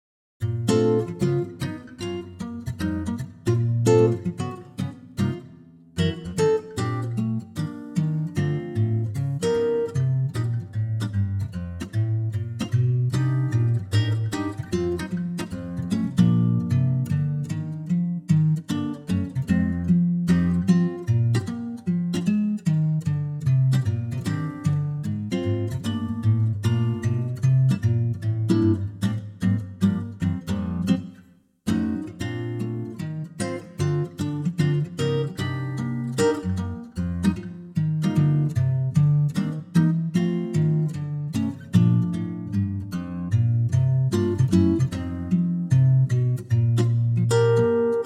key - Bb - vocal range - D to D